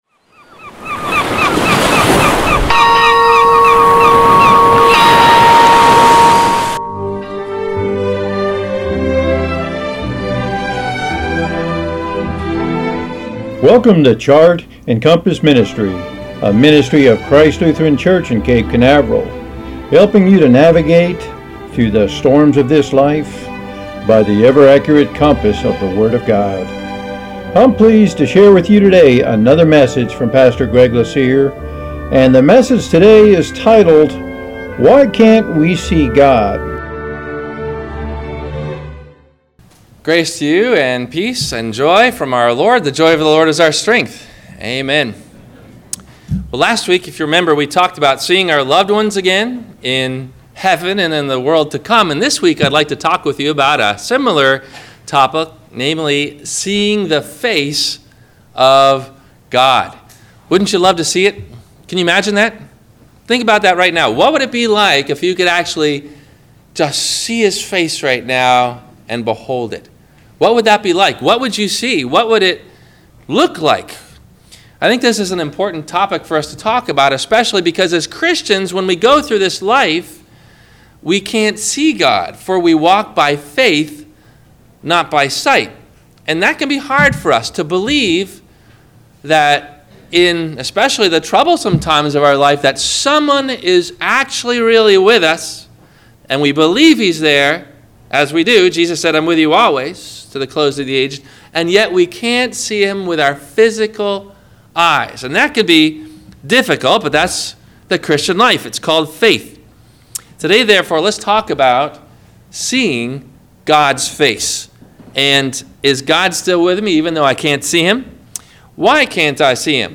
Why Can’t We See God? – WMIE Radio Sermon – June 05 2017
No Questions asked before the Sermon message: